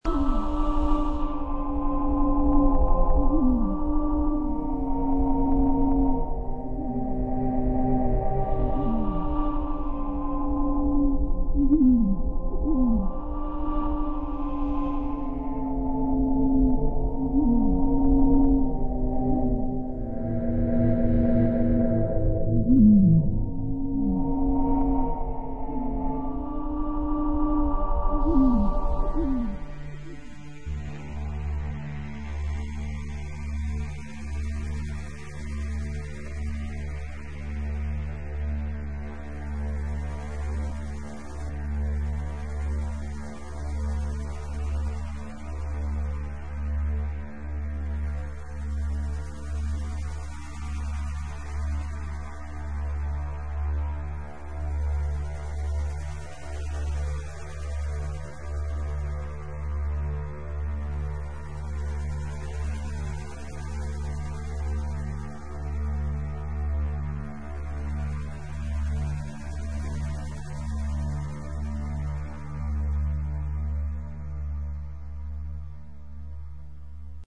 72 Stunden multimediale Kunst 2003